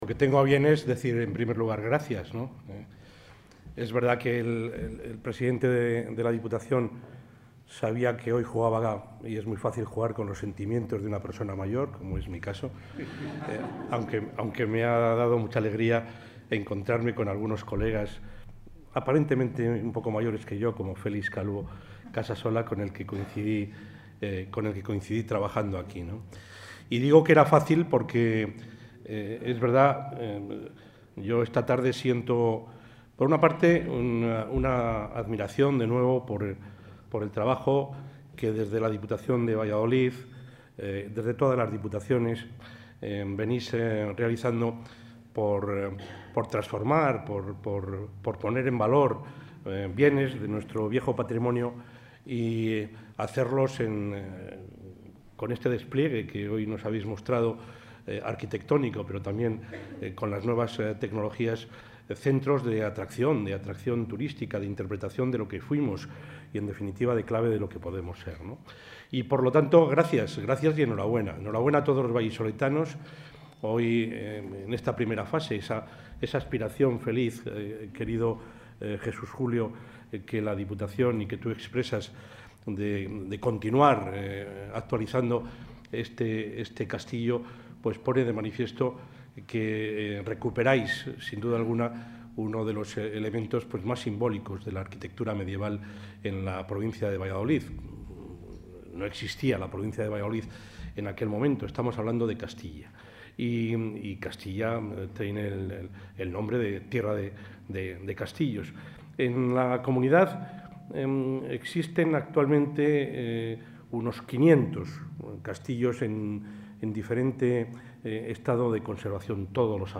Audio presidente.
El presidente de la Junta de Castilla y León, Juan Vicente Herrera, ha asistido hoy a la apertura del Castillo de Fuensaldaña como Centro de los Castillos de Valladolid.